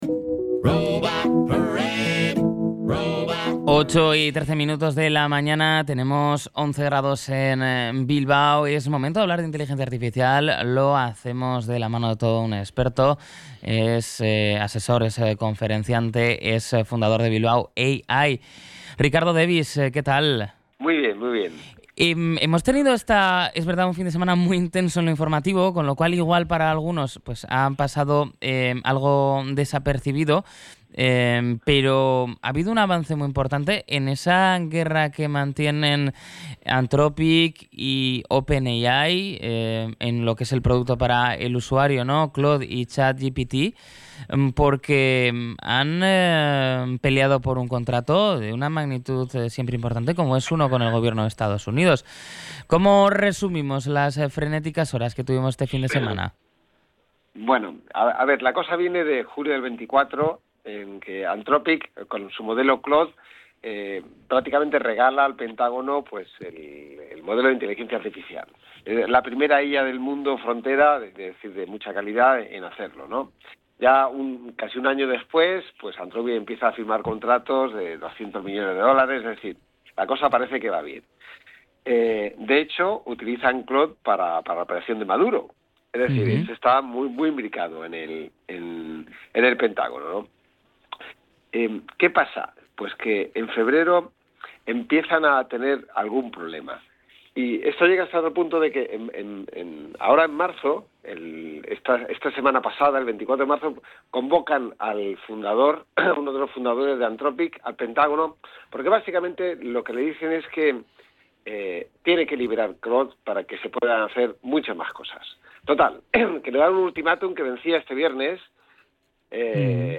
Podcast Tecnología